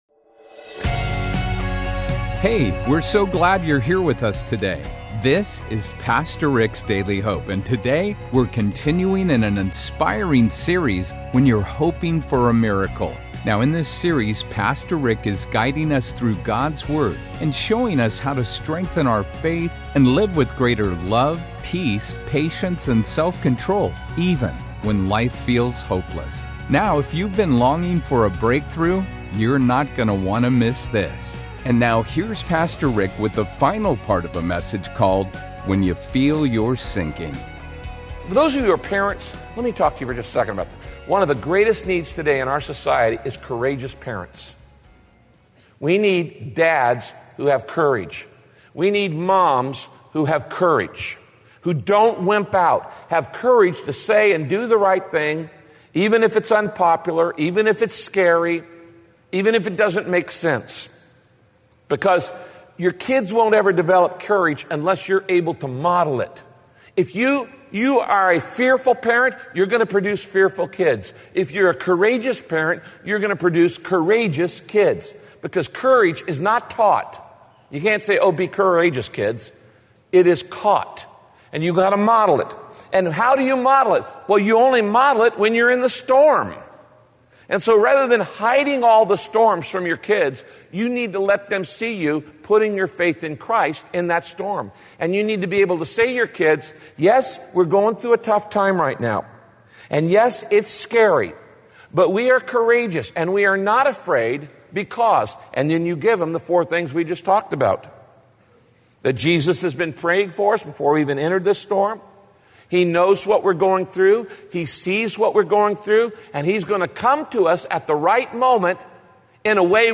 What storm is sinking you right now? In this message, Pastor Rick explains how you can have courage to face life’s storms when you stay focused on Jesus, don’t…